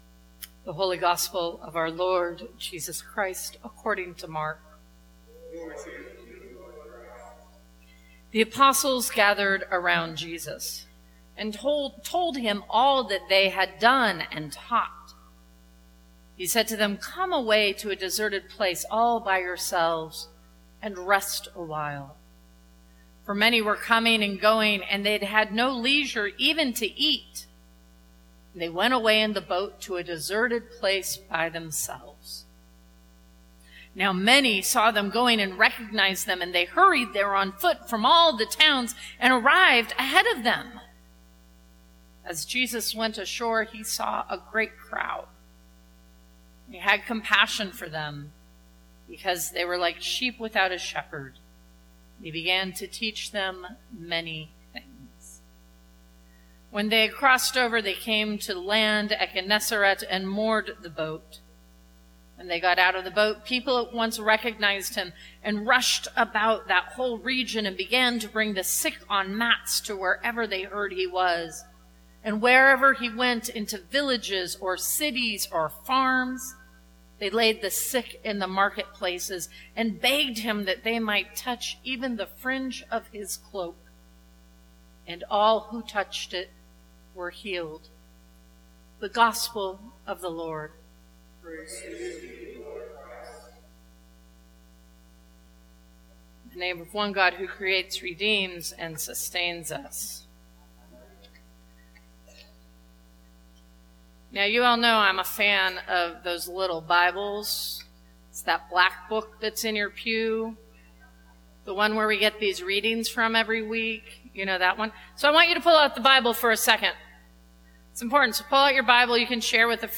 Sermons from St. Cross Episcopal Church Practicing Compassion Jul 25 2018 | 00:18:38 1x 00:00 / 00:18:38 Subscribe Share Apple Podcasts Spotify Overcast RSS Feed Share Link Embed